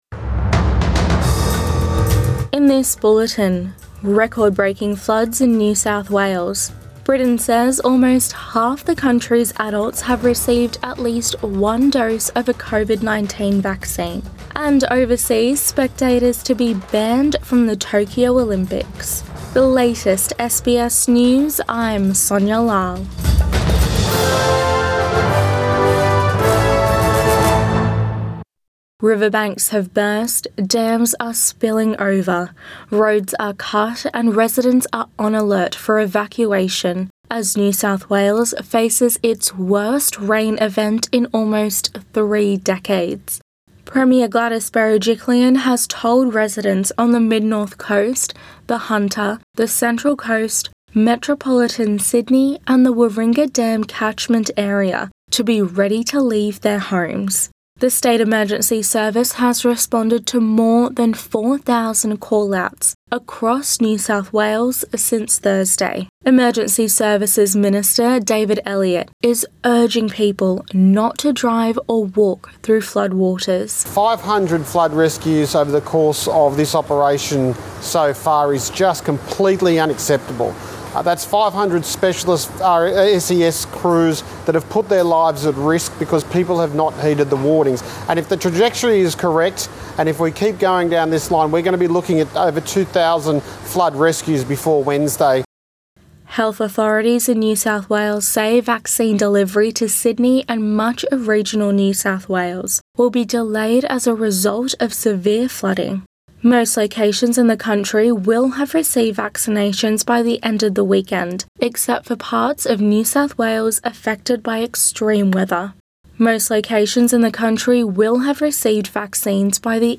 AM bulletin March 21 2021